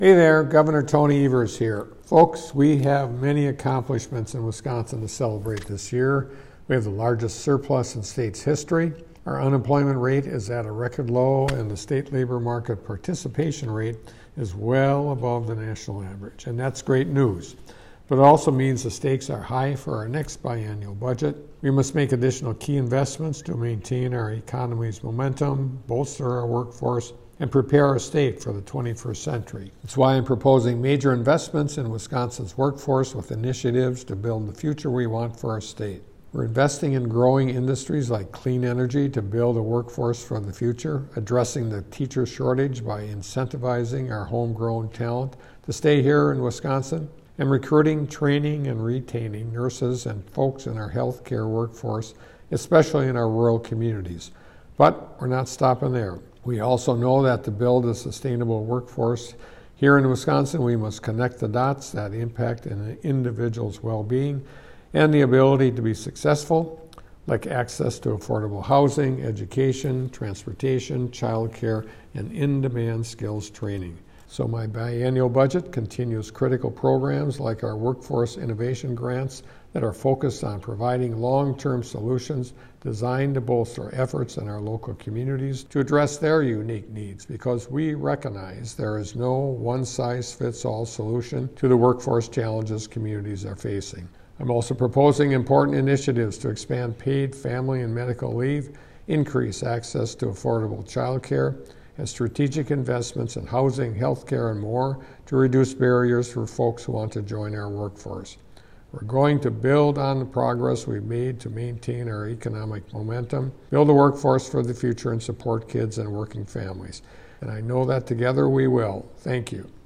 Democratic Radio Address: Transcript &